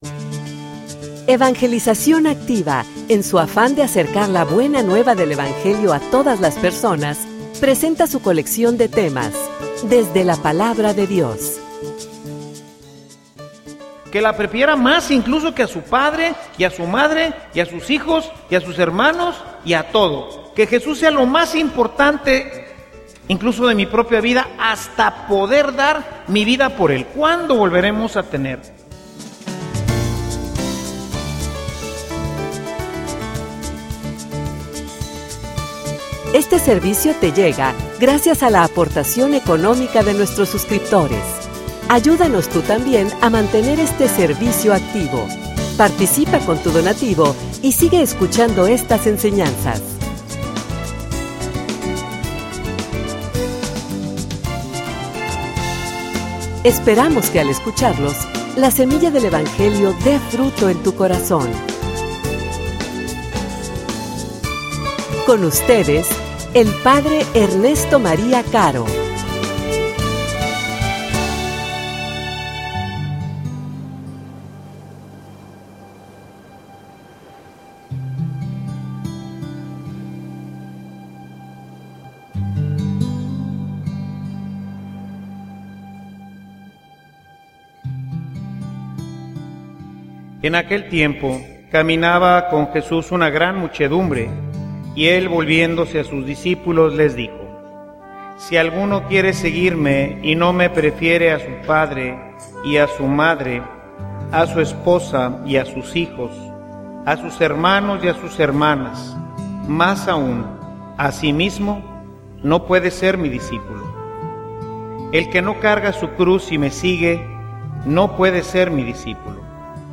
homilia_Una_decision_libre_y_bien_pensada.mp3